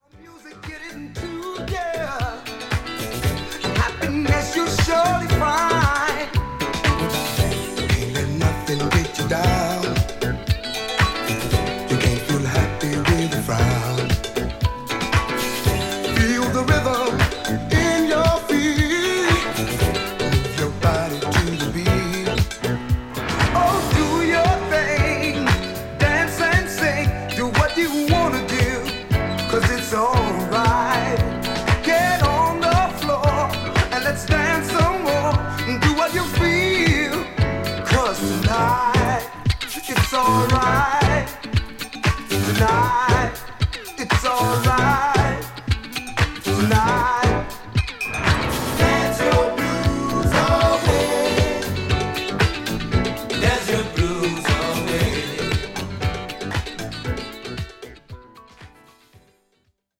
SOUL / FUNK / RARE GROOVE / DISCO